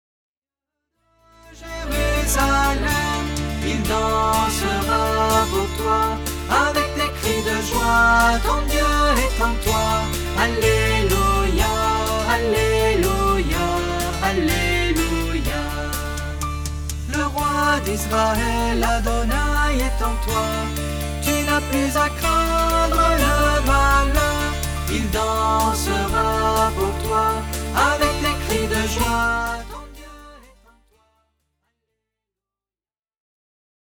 Louange (431)